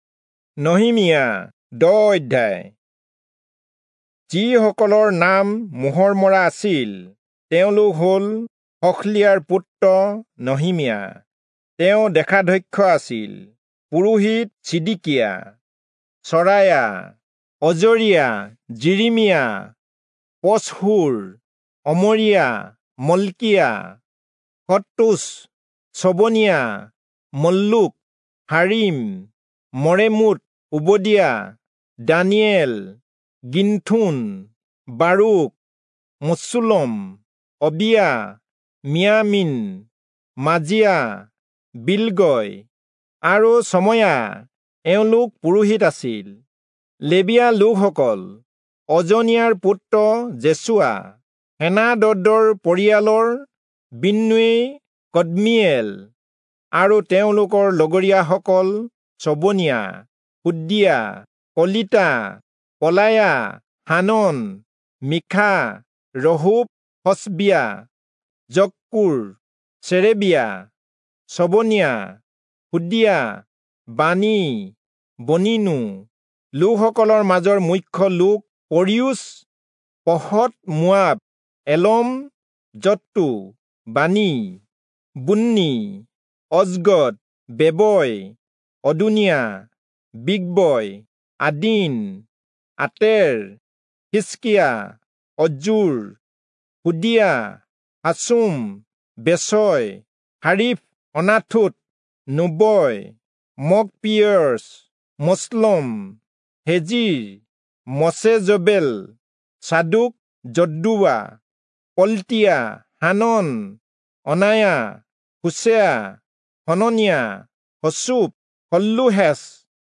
Assamese Audio Bible - Nehemiah 8 in Ervml bible version